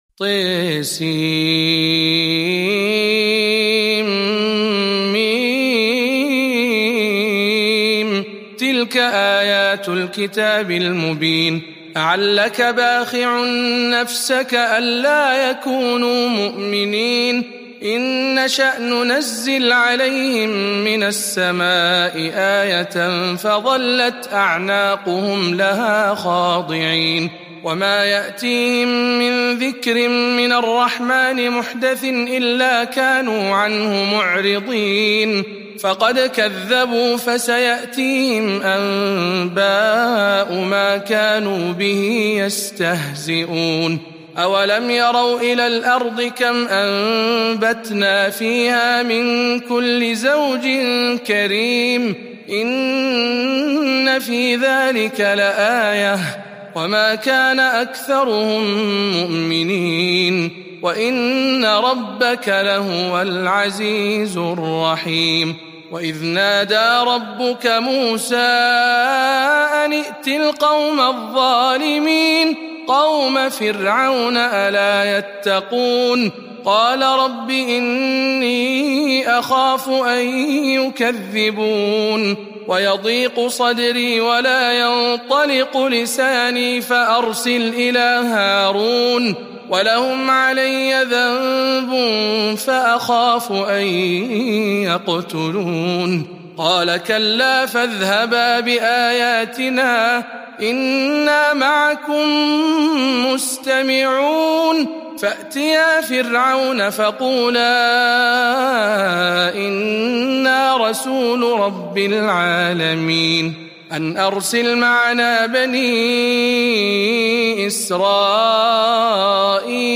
سورة الشعراء برواية شعبة عن عاصم